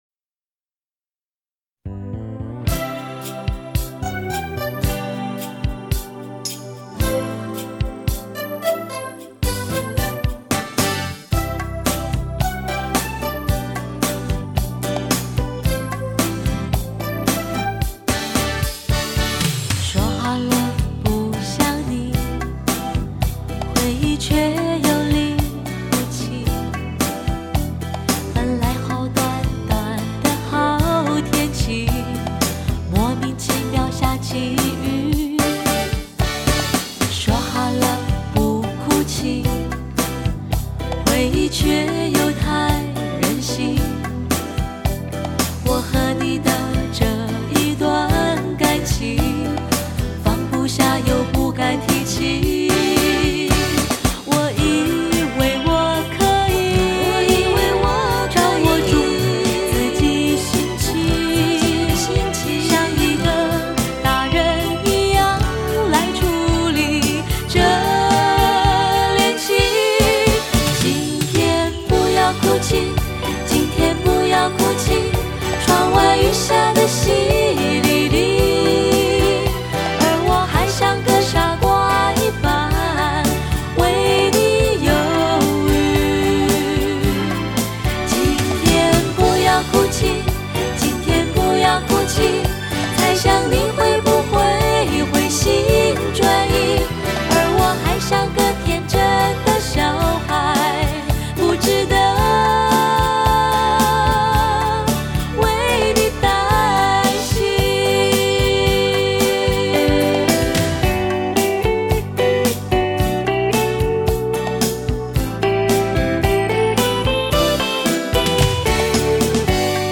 清新恬淡的音乐组合，以优美谐和的声音叩开听者的心扉，去聆听她们的歌声吧，特别当你浮躁、焦虑、郁闷和莫名烦恼之时····